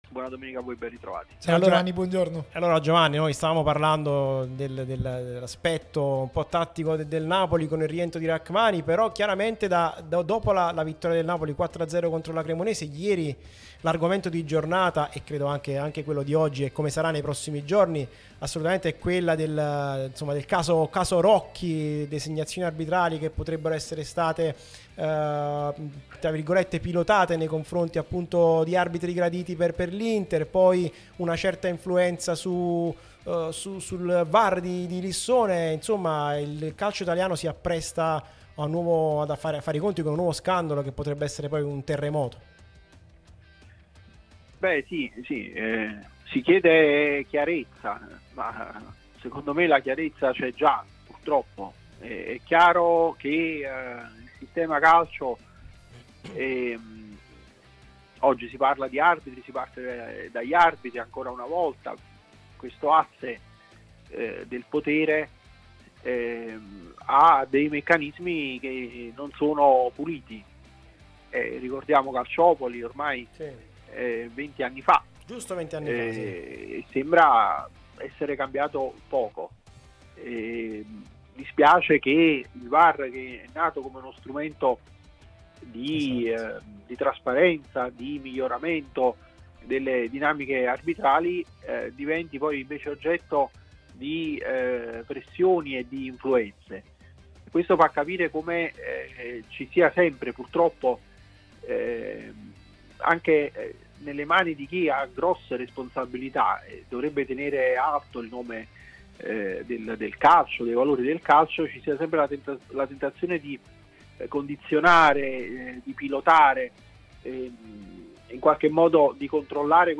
è intervenuto sulla nostra Radio Tutto Napoli